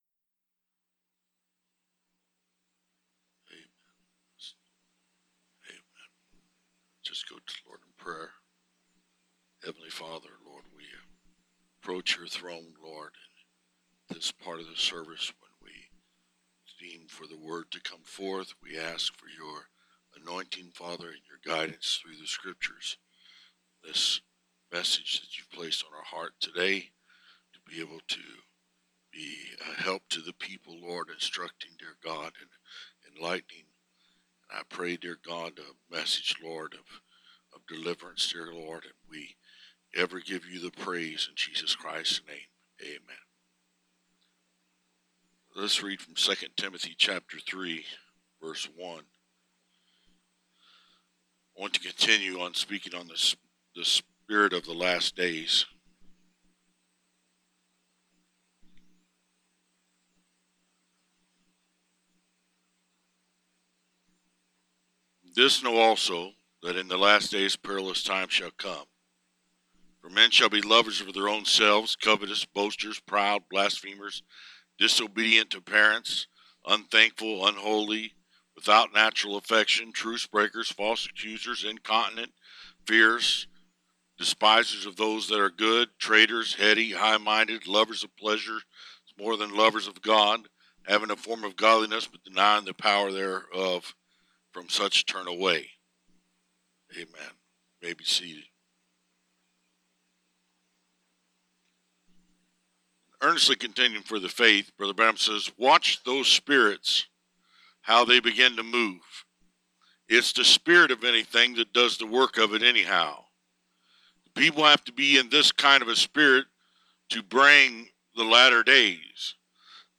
Preached